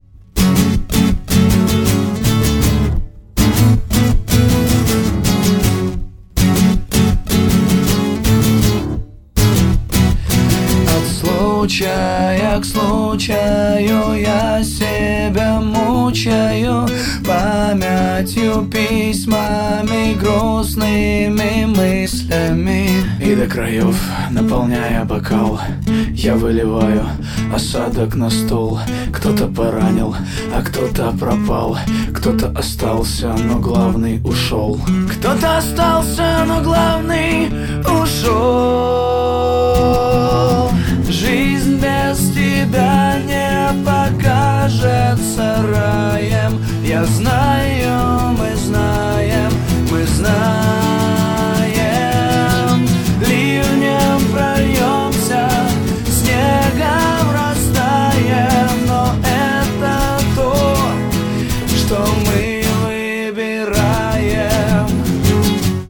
• Качество: 128, Stereo
гитара
мужской голос
громкие
струнные
энергичные
русский рок